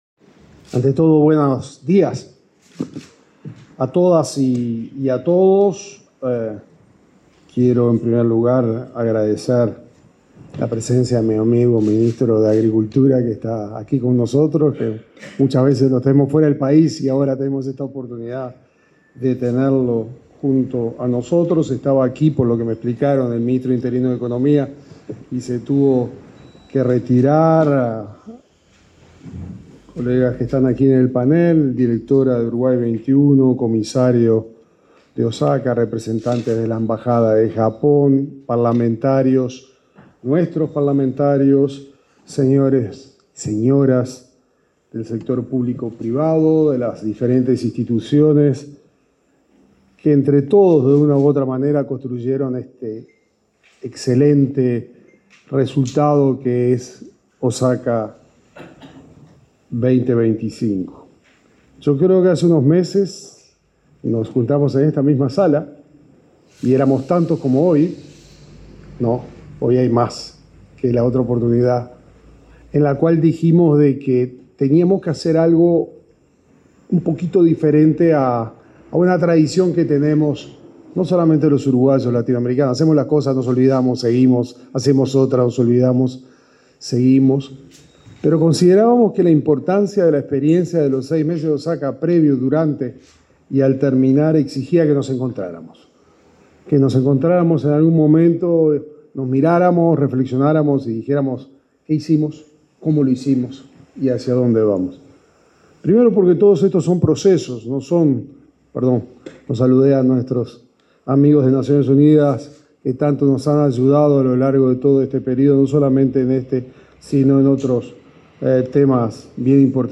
Palabras del ministro de Relaciones Exteriores, Mario Lubetkin